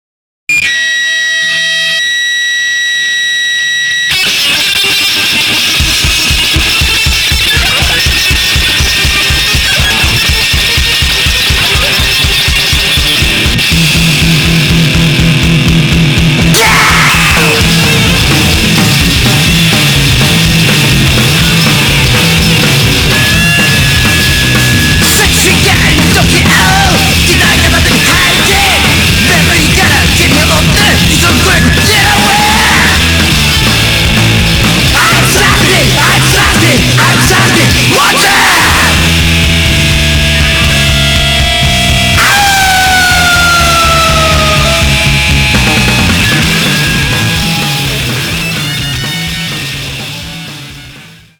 ロックンロール